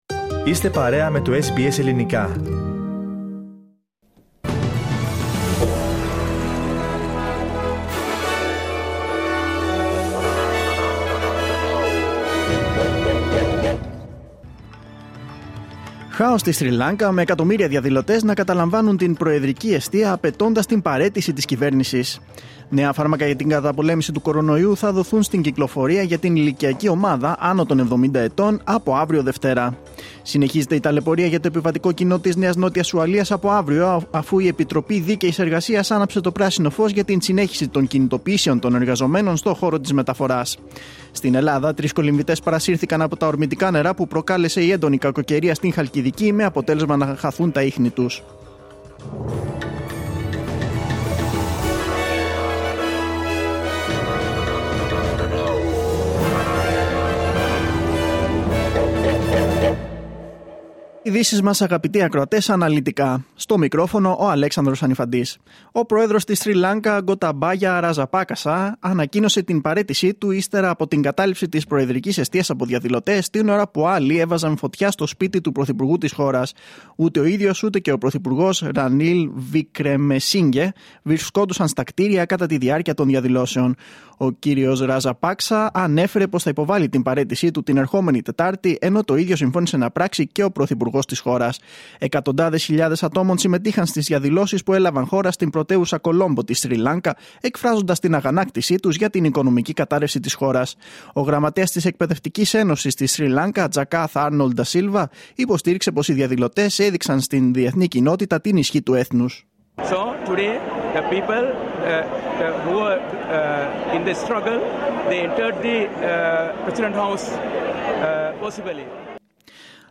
Δελτίο Ειδήσεων Κυριακή 10.7.2022